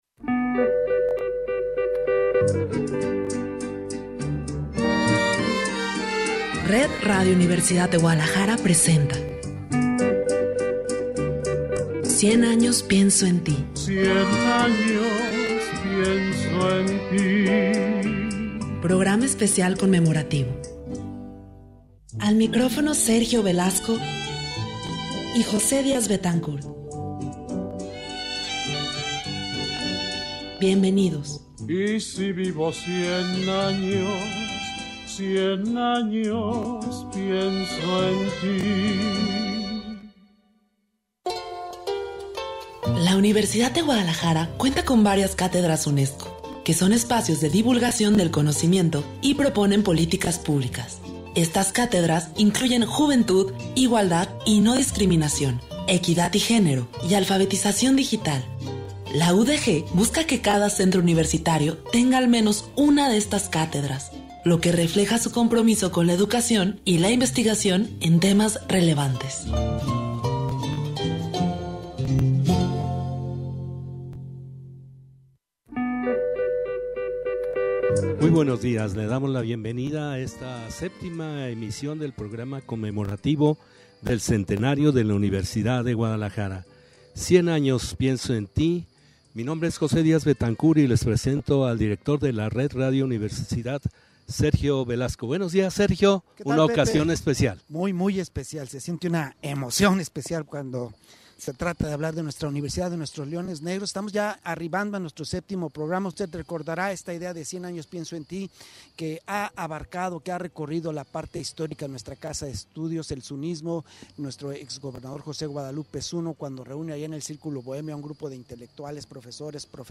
Comenzamos #100AñosPiensoEnTi ?? desde el Estadio Jalisco.